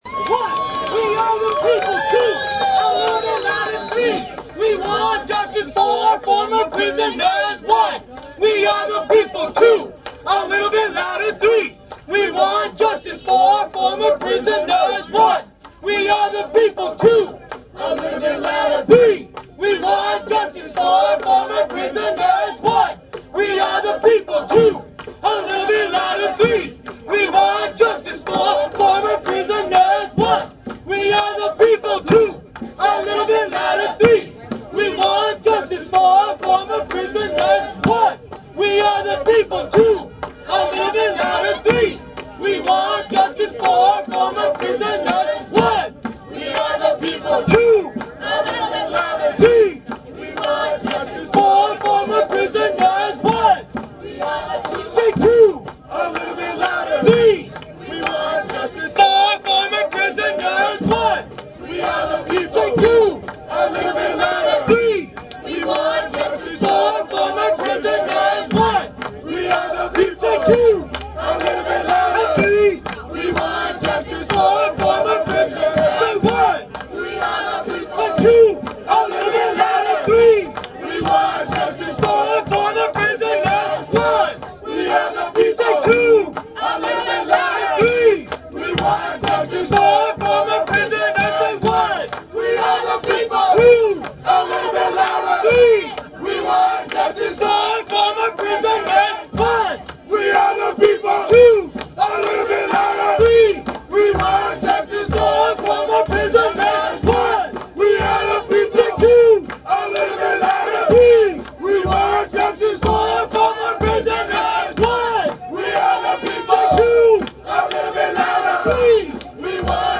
Audio from 1/26 demonstration at Oakland Mayor Jerry Brown's loft apartment protesting his plans to implement a curfew for all future probationers and parolees between 10pm and 6am.